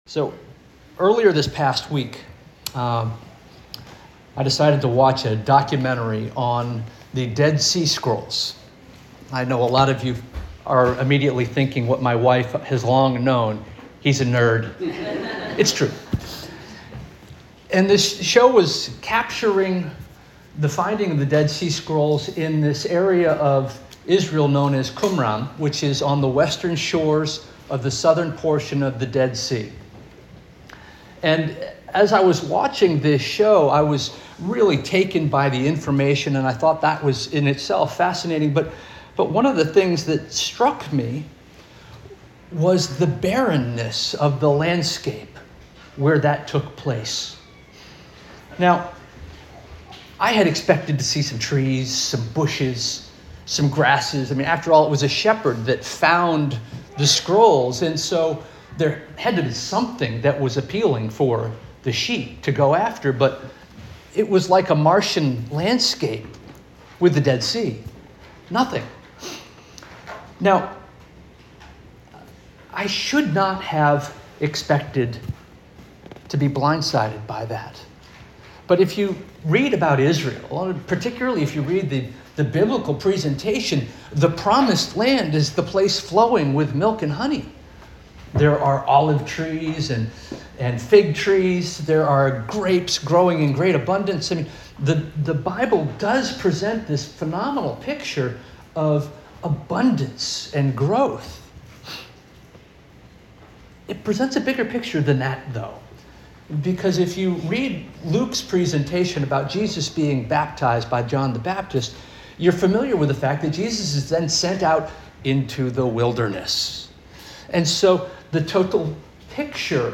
August 10 2025 Sermon